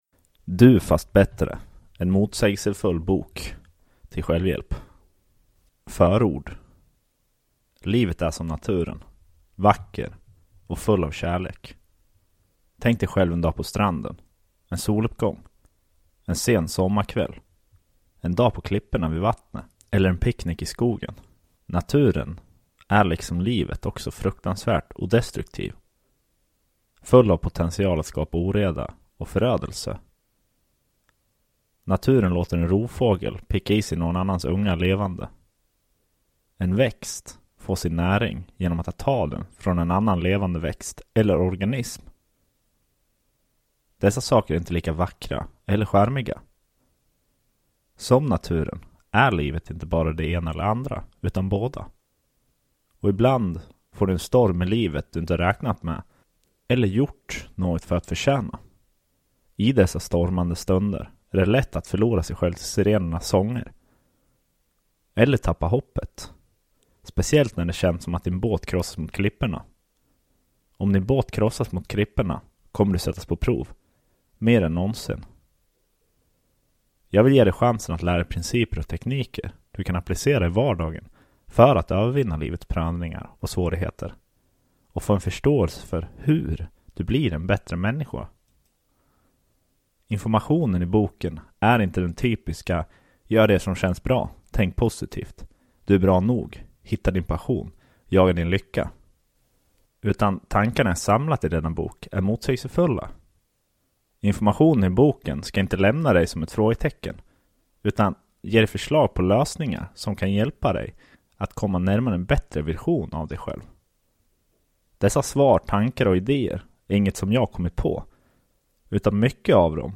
Du fast bättre, en motsägelsefull bok till självhjälp – Ljudbok – Laddas ner